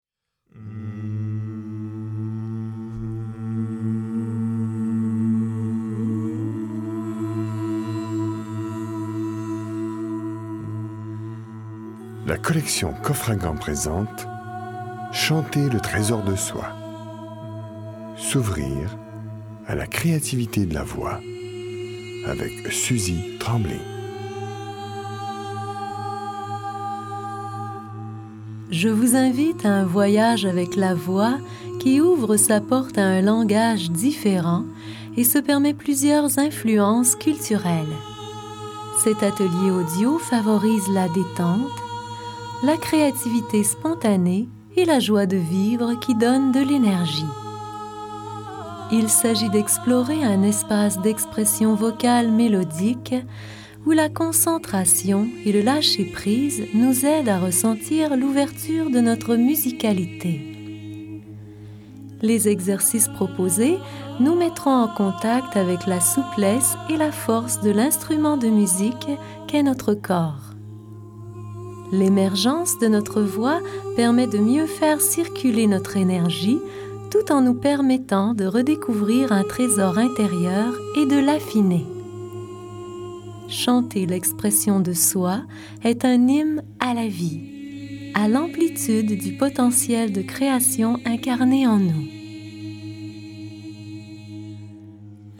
Diffusion distribution ebook et livre audio - Catalogue livres numériques
Atelier audio